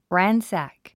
発音 rǽnsæk ランサック